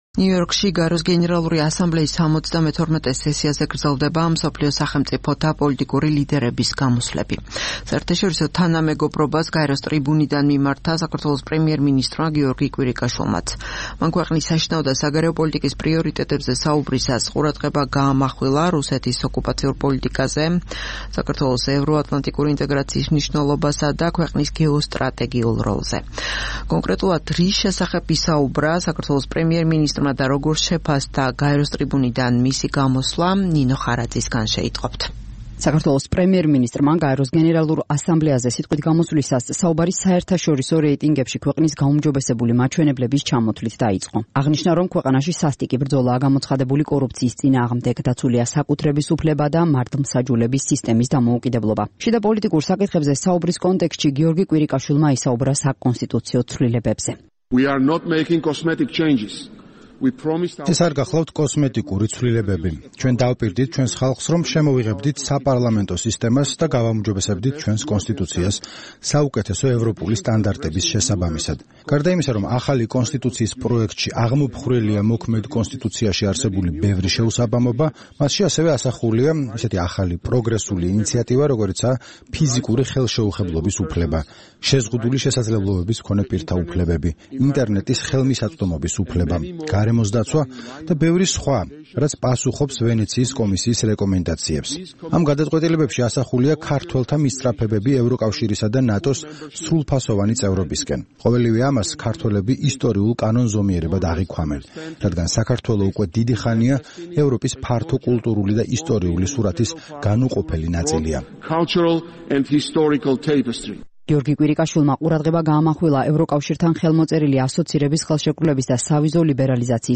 პრემიერის სიტყვა გაეროს ტრიბუნიდან
21 სექტემბერს გაეროს გენერალური ასამბლეის 72-ე სესიის გენერალურ დებატებზე სიტყვით წარდგა საქართველოს პრემიერ-მინისტრი გიორგი კვირიკაშვილი. მან ქვეყნის შიდა პოლიტიკის პრიორიტეტულ ასპექტებზე ილაპარაკა, ყურადღება გაამახვილა რუსეთის ოკუპაციურ პოლიტიკასა და ევროატლანტიკური ინტეგრაციის მნიშვნელობაზე.